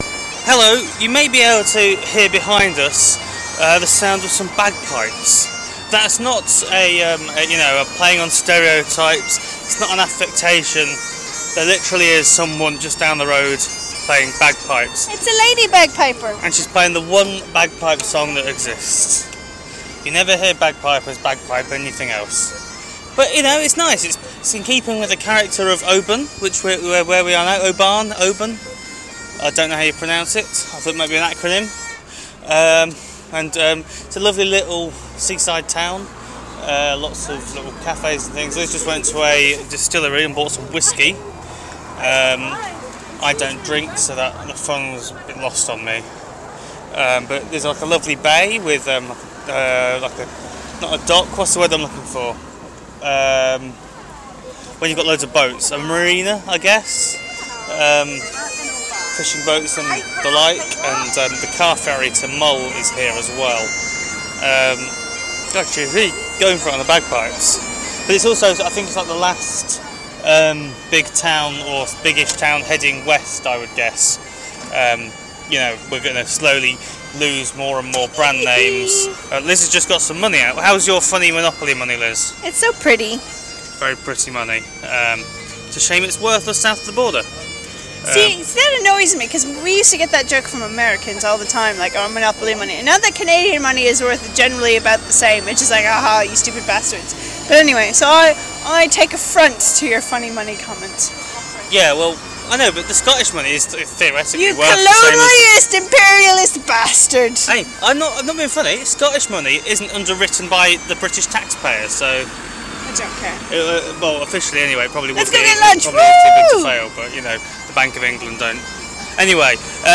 Bagpipes in Oban